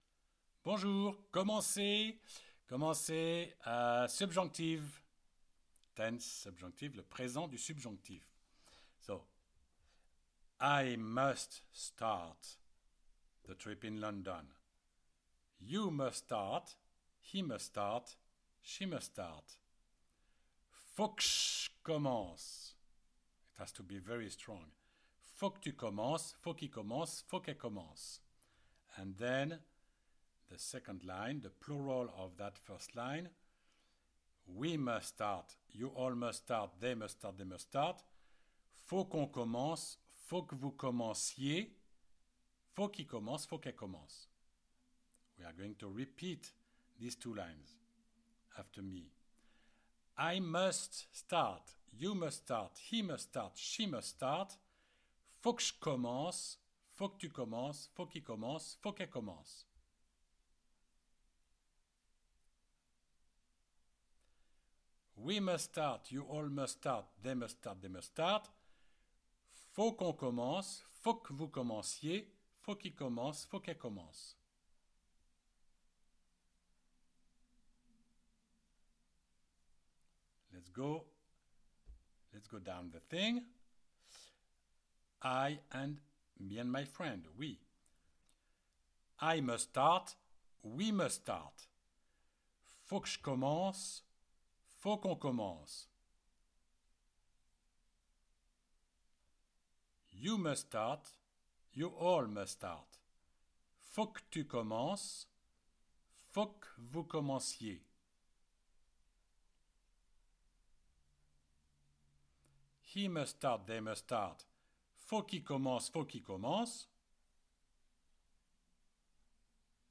CLICK ON THE PLAY BUTTON BELOW TO PRACTICE ‘PARLER’, ‘TO SPEAK’, AT THE PRESENT TENSE